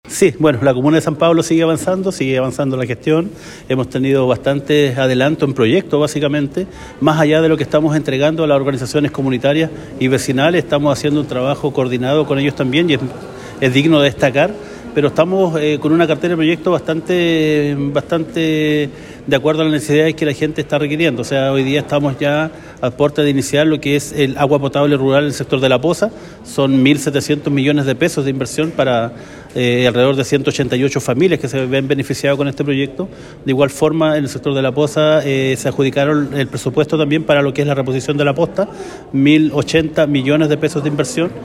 El Alcalde Juan Carlos Soto, señaló que se han tenido muchos avances en proyectos que van para subsanar la necesidad de la población, en el caso de La Poza se aprobaron cerca de 1700 millones para el Agua Potable Rural y 1080 millones para la posta del sector, que beneficiará a cerca 188 familias.